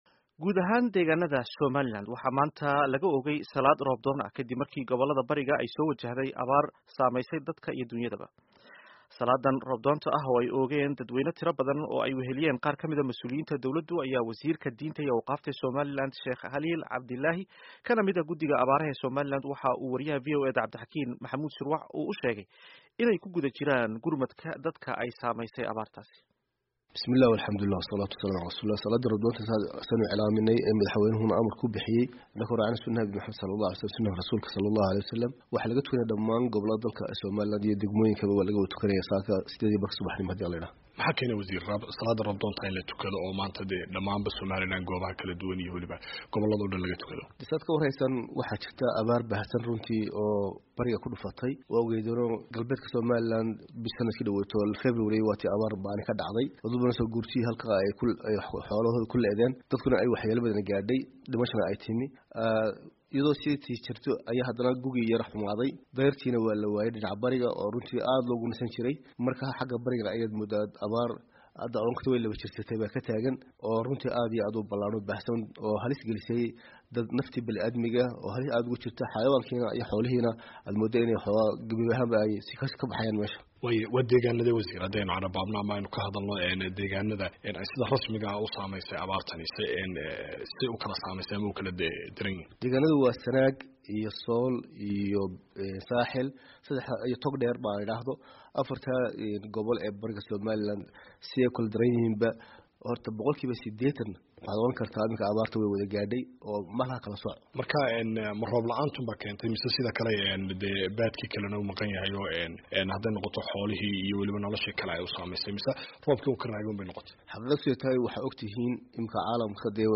Dhageyso wareysiga abaarta